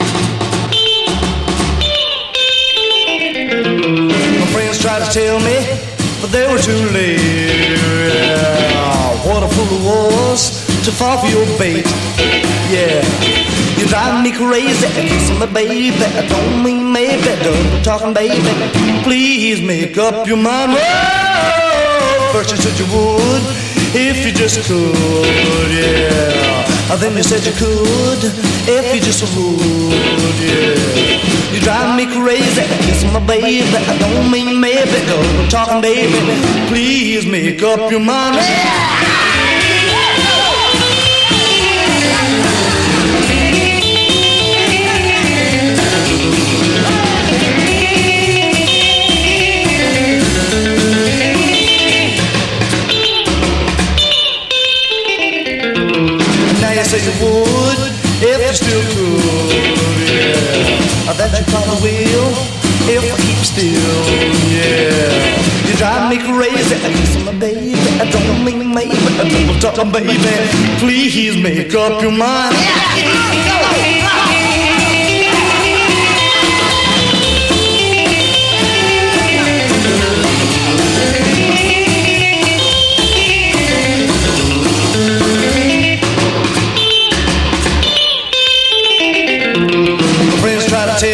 エネルギッシュなロカビリー・サウンドが堪能できます。